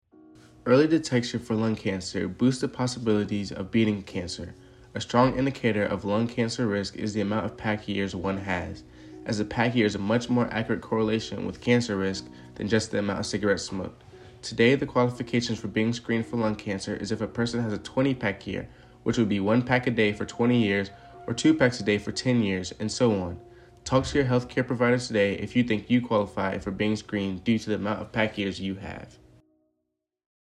Radio PSAs